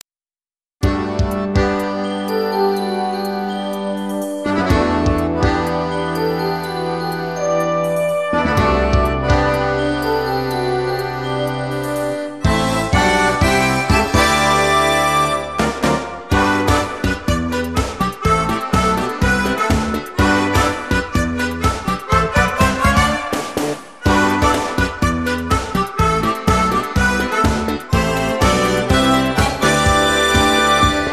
Symphonic Rockfeeling